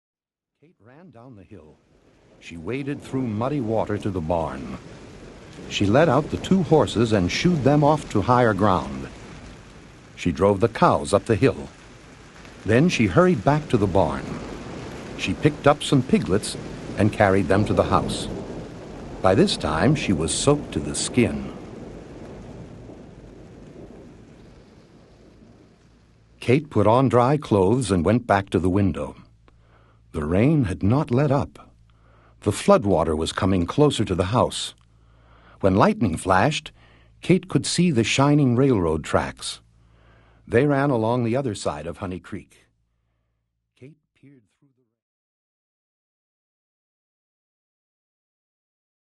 When the famed Regent Diamond is stolen, Julieta is in the middle of a high stakes mystery. With a bilingual family and international travel, the full cast portrays a range of accents, as well as phrases in Spanish and French, with panache.